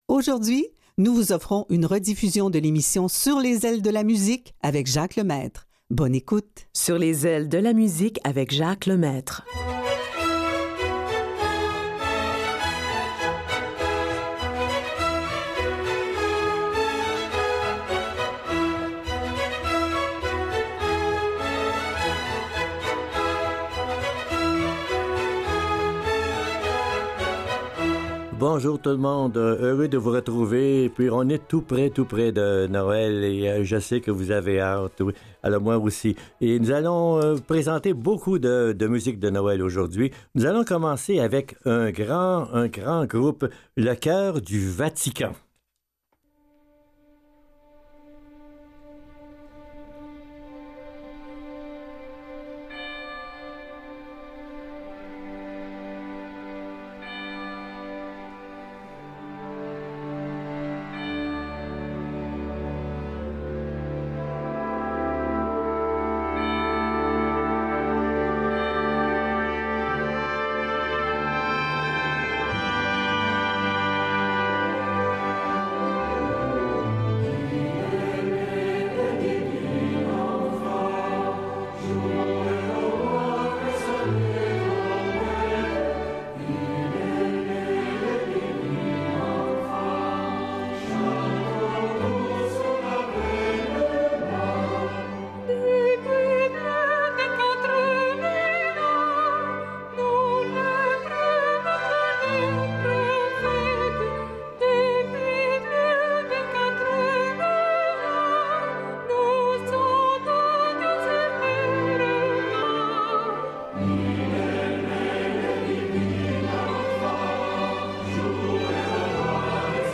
musique classique et populaire